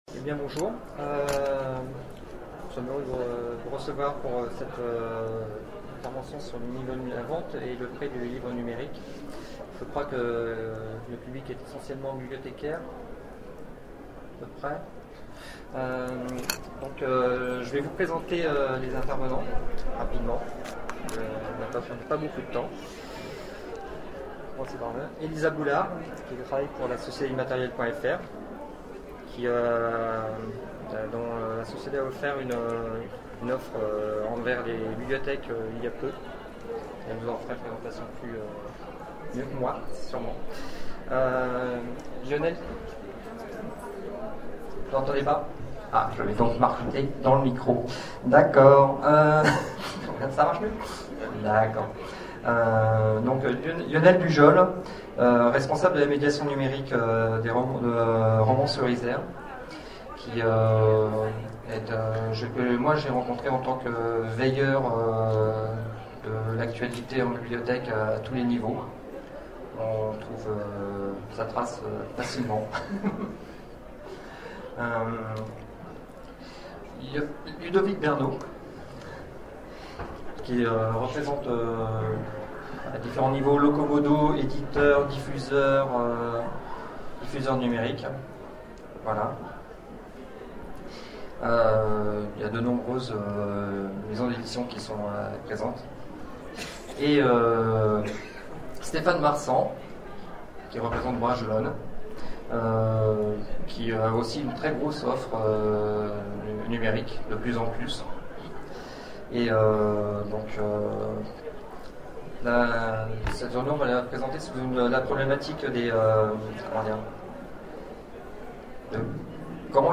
Zone Franche 2012 : Conférence Vendre et prêter des livres numériques
Table ronde